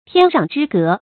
天壤之隔 tiān rǎng zhī gé 成语解释 天上和地下的间隔，形容差别极大。